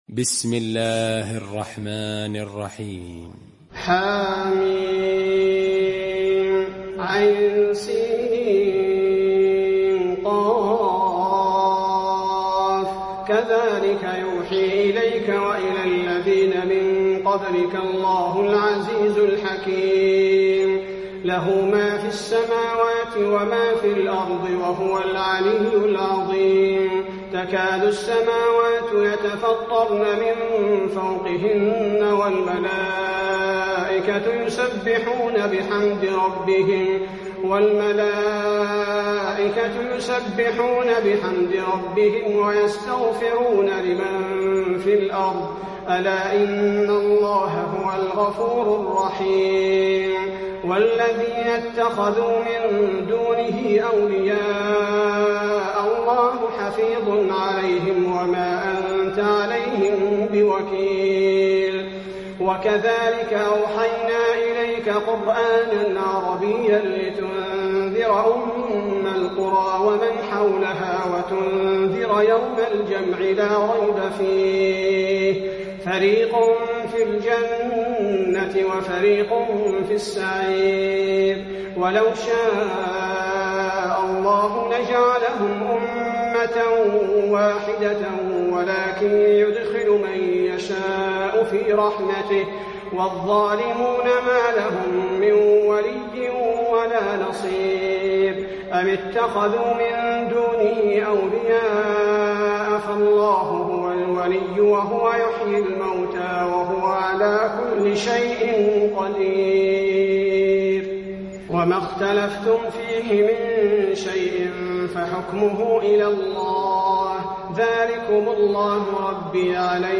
المكان: المسجد النبوي الشورى